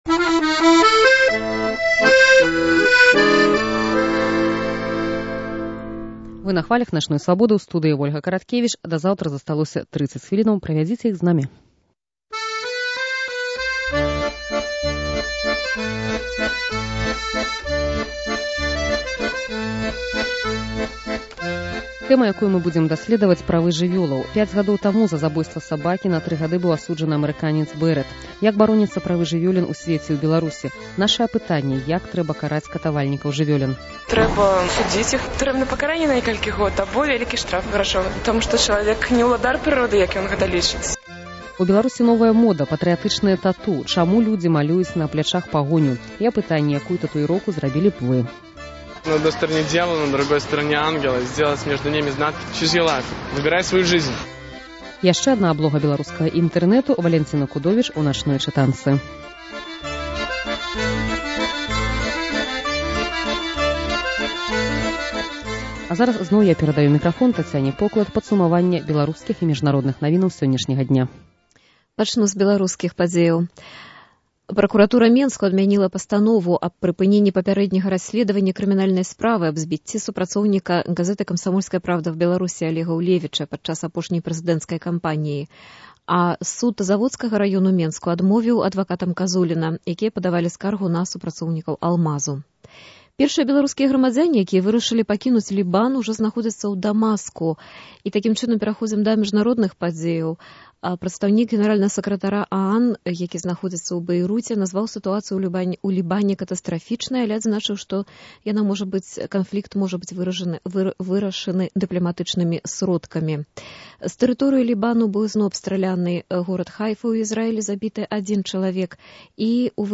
Прадстаўленьне і чытаньне аўтара.